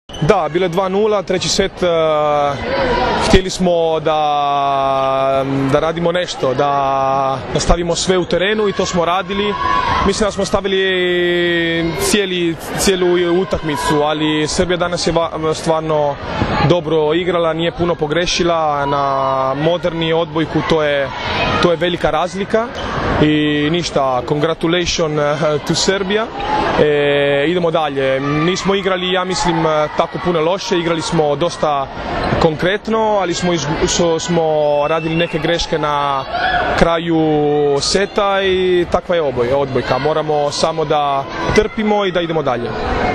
IZJAVA DRAGANA TRAVICE